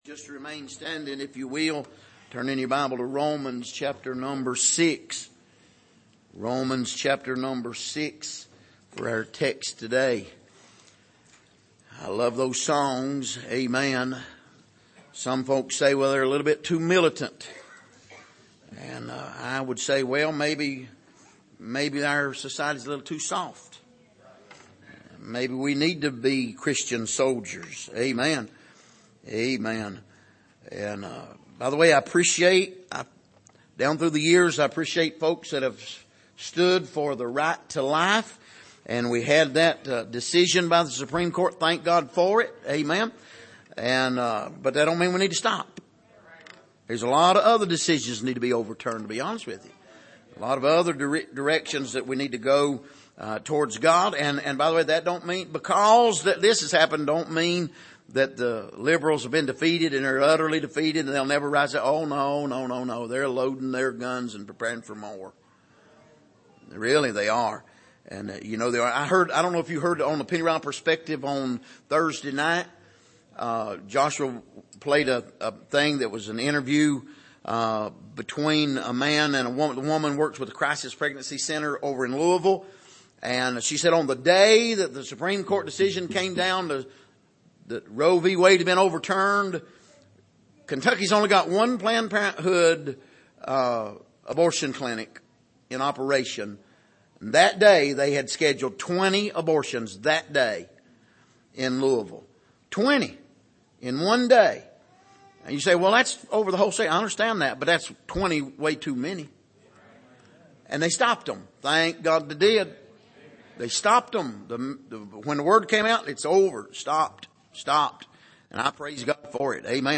Passage: Romans 6:12-23 Service: Sunday Morning